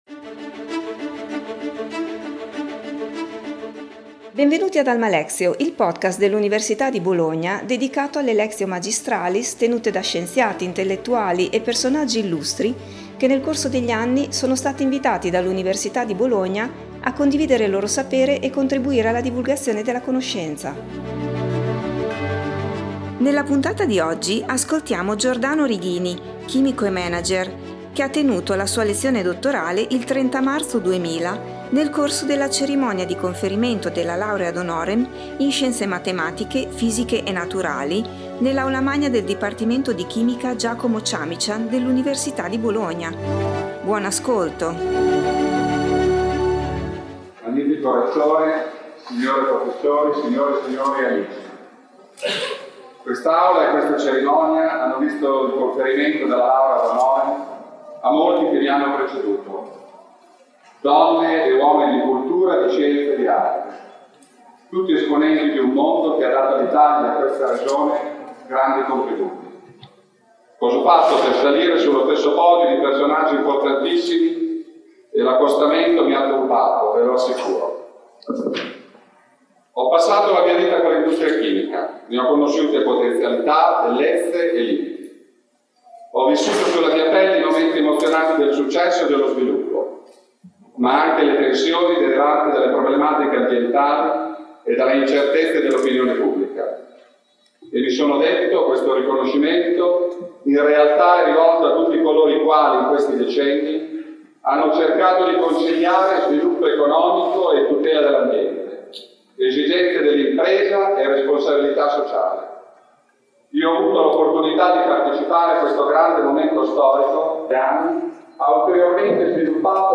Lectio magistralis
fisiche e naturali nell’Aula Magna del Dipartimento di Chimica “Giacomo Ciamician” dell’Università di Bologna.